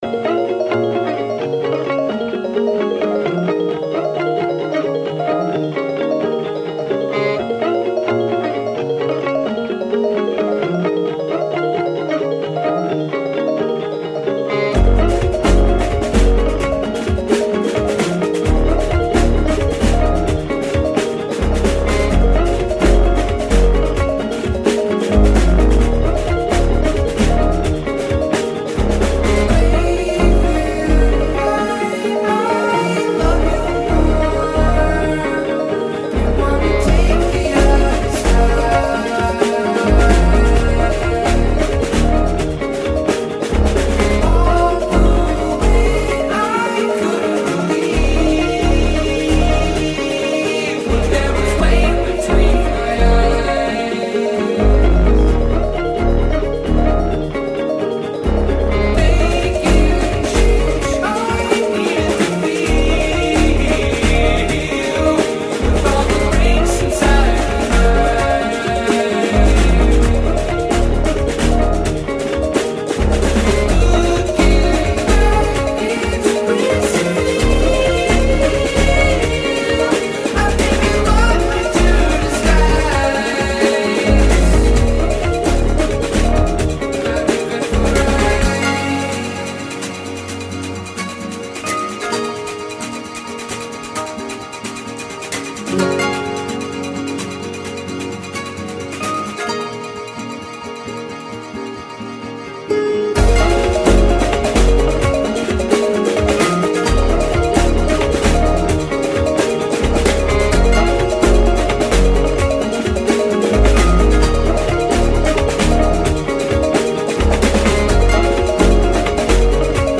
electronic project